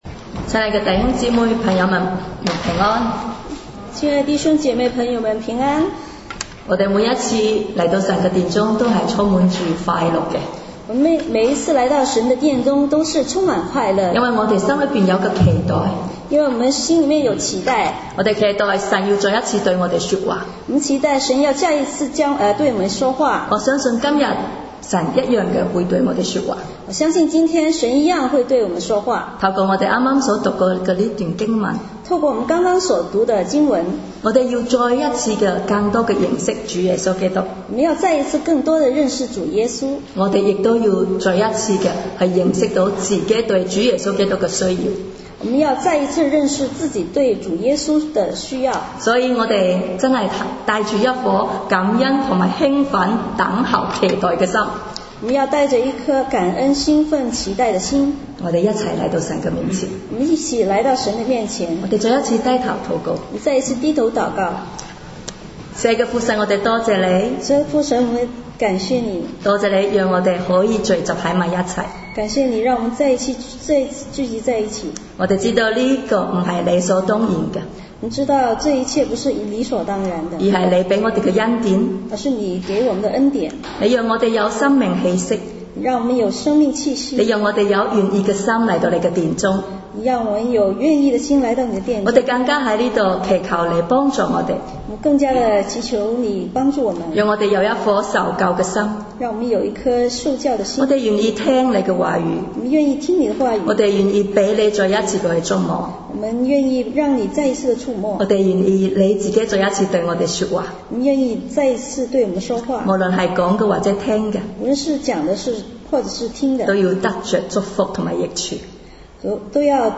讲道